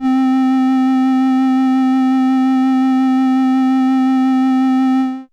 電卓、ミュージックシーケンサー搭載の電子キーボード VL-1
◆Flute
VL-1_60_C-4_Flute.wav